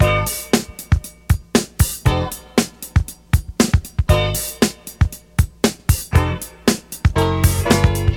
• 117 Bpm Fresh Disco Drum Groove G# Key.wav
Free drum loop sample - kick tuned to the G# note. Loudest frequency: 1694Hz
117-bpm-fresh-disco-drum-groove-g-sharp-key-06c.wav